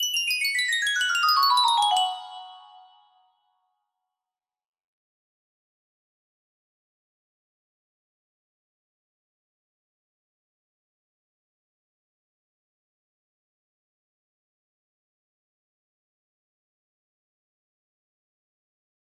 Rainbow music box melody
Full range 60